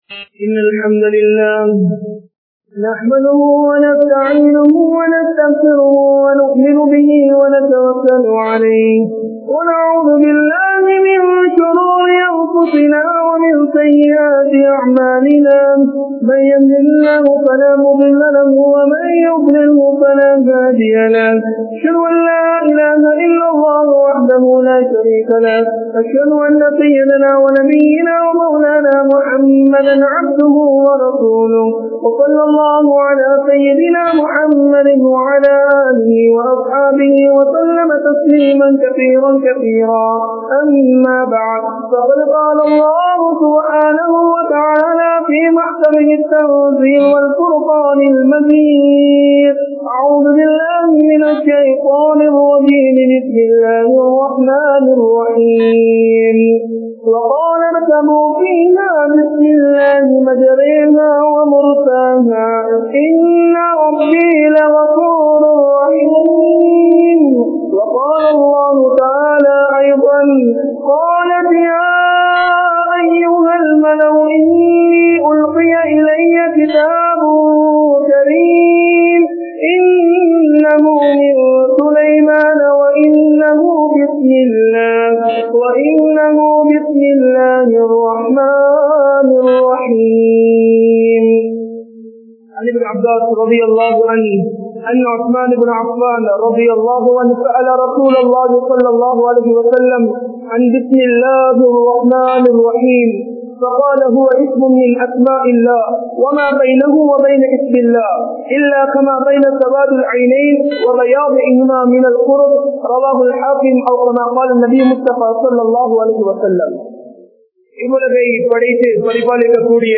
Bishmillah Solvathin Sirappuhal (பிஸ்மில்லாஹ் சொல்வதின் சிறப்புகள்) | Audio Bayans | All Ceylon Muslim Youth Community | Addalaichenai
Colombo 04, Majma Ul Khairah Jumua Masjith (Nimal Road)